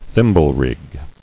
[thim·ble·rig]